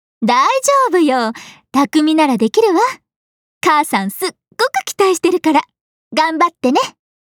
cha15miwa_voice_sample.mp3